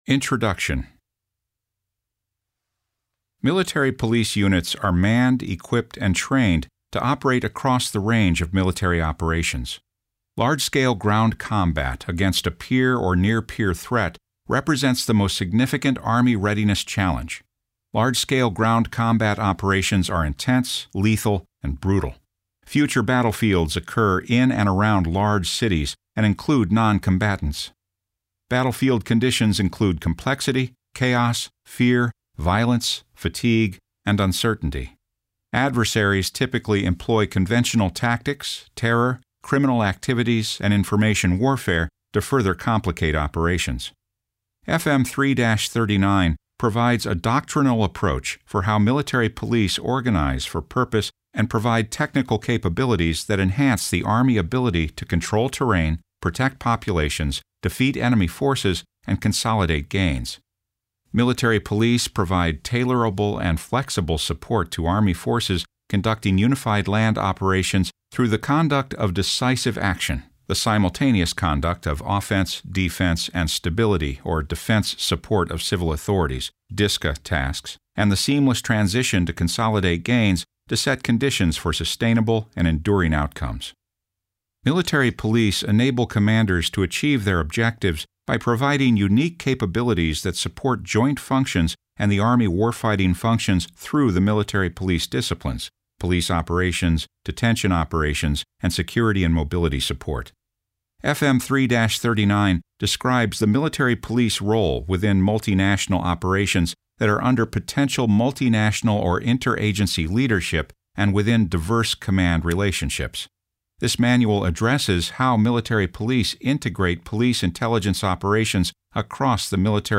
Army Doctrine Audiobook Download Page FM 3-39 describes the operational doctrine of the Military Police Corps Regiment.